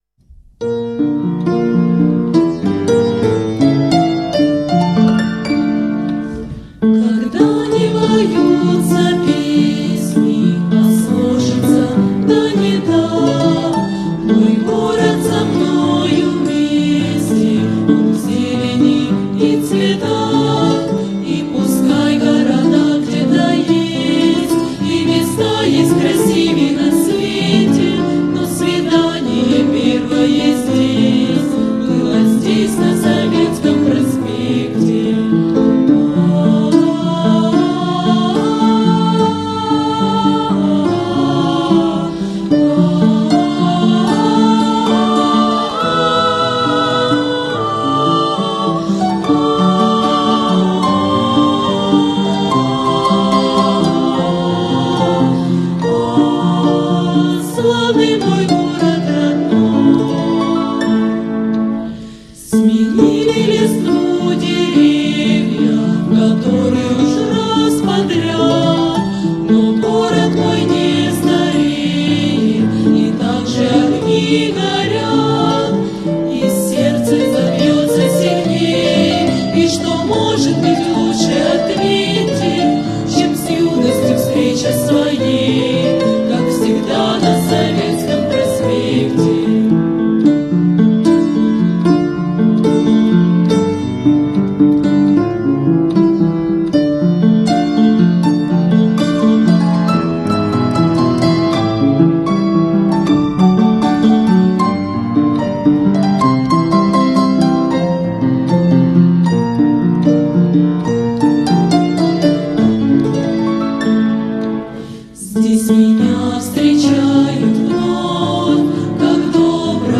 Это лирическая хоровая песня.
И сейчас она звучит в исполнении вокального ансамбля музыкального училища.